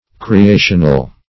\Cre*a"tion*al\ (-al)